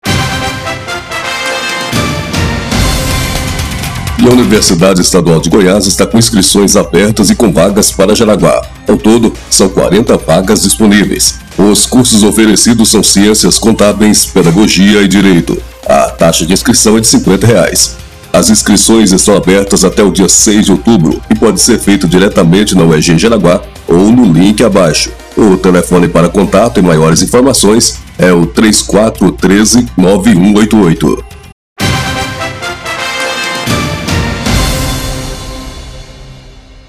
VINHETA-UEG.mp3